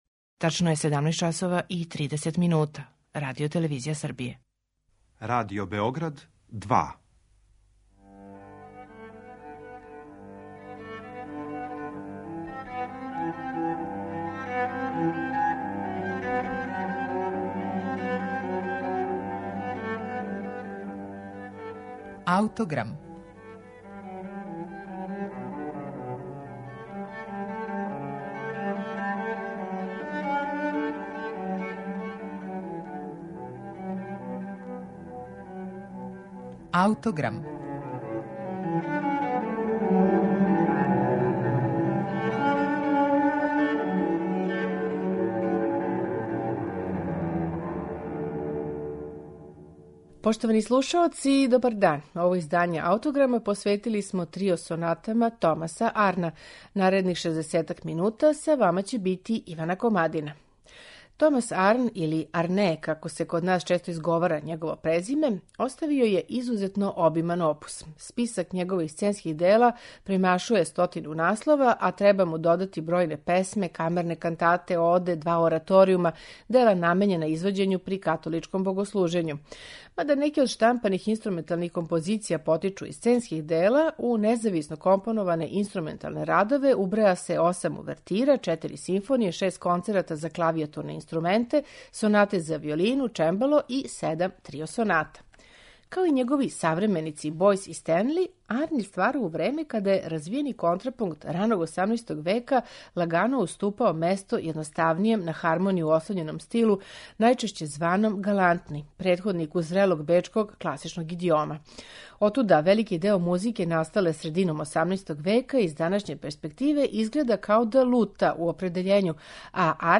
седам трио соната